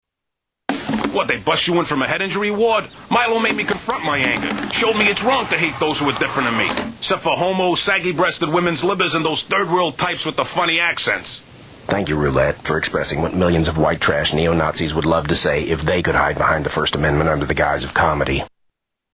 Funny accents